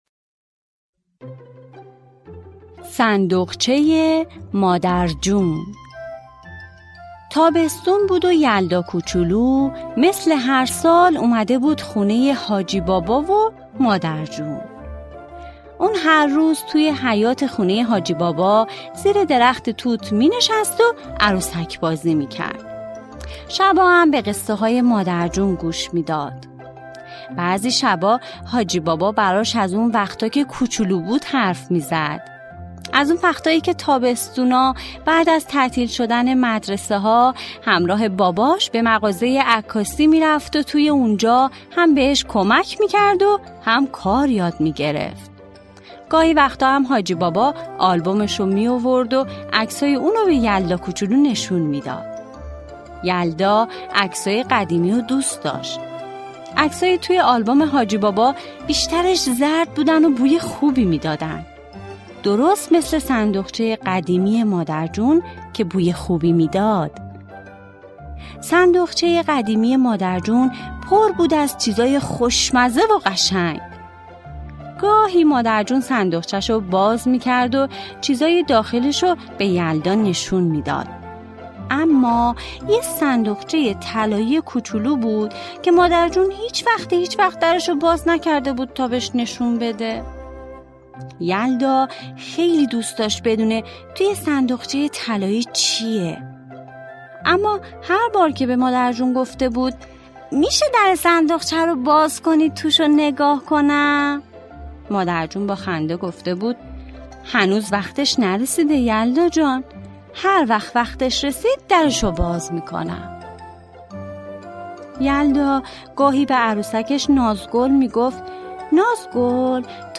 قصه های کودکانه؛ صندوقچه مادر جون
قصه کودک